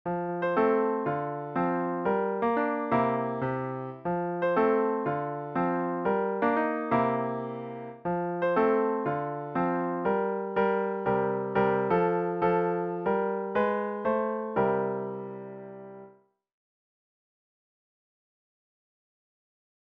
最後の4～6小節で循環コードになっていると思われるコードを弾きながら右手でメロディーを作っていきます